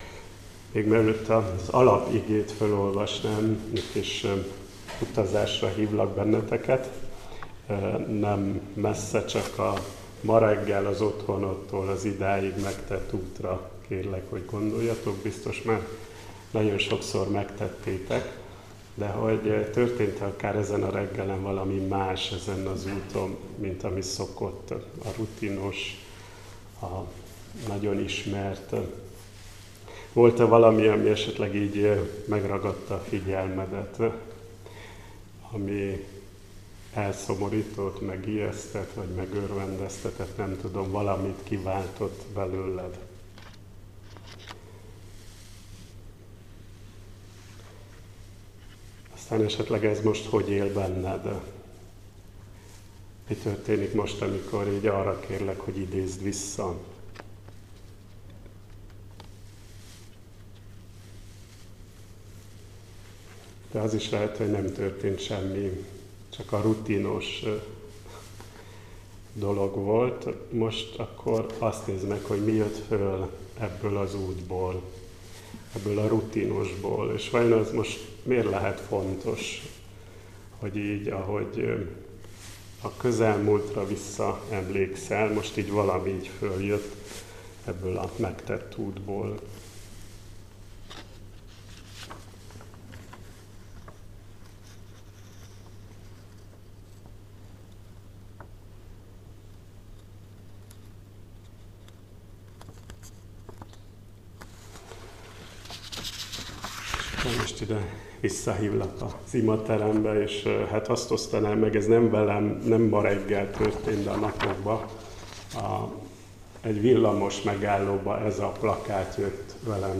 Áhítat, 2025. november 18.